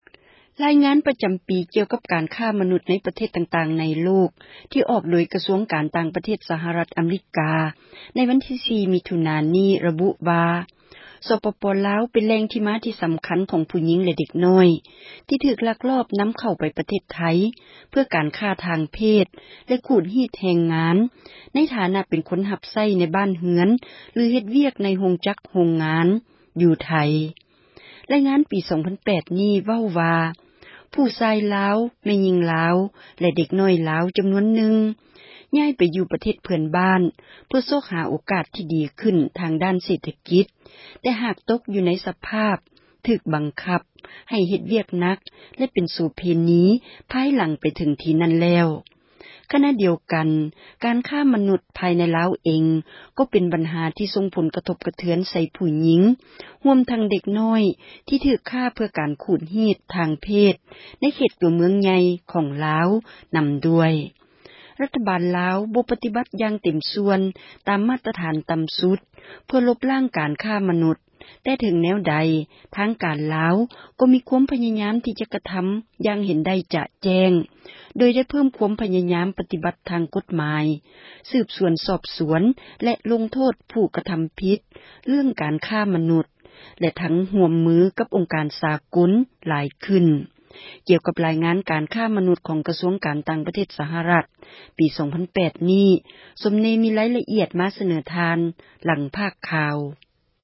ສຽງແລ່ງໂສເພນີໄທຢູ່ລາວ